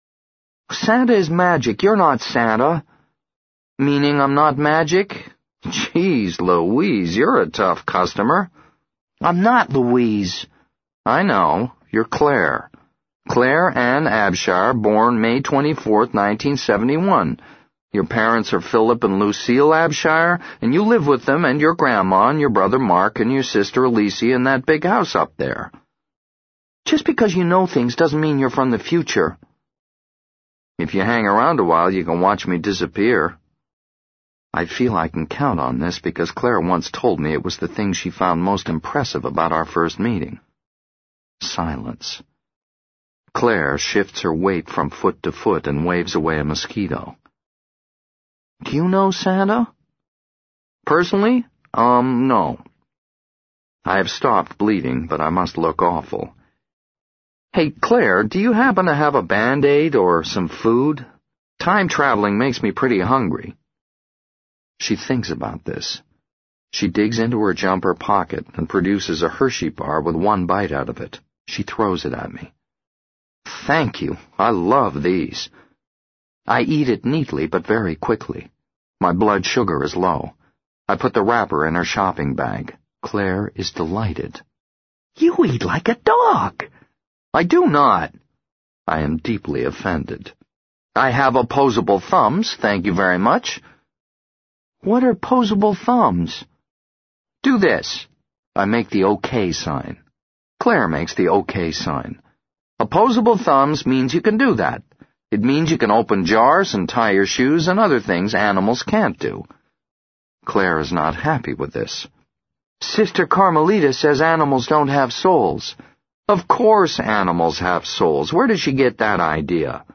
在线英语听力室【时间旅行者的妻子】34的听力文件下载,时间旅行者的妻子—双语有声读物—英语听力—听力教程—在线英语听力室